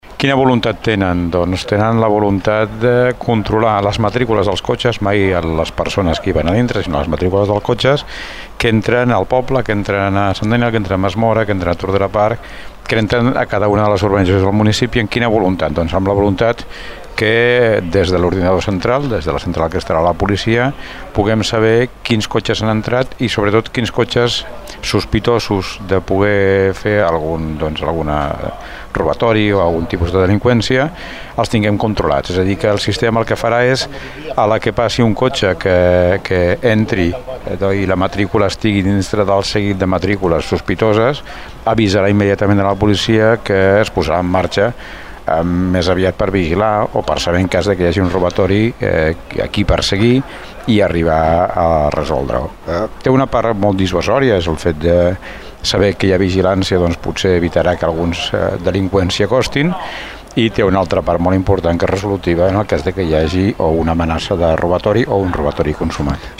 Ho explica l’alcalde de Tordera, Joan Carles Garcia.
alcalde-càmeres.mp3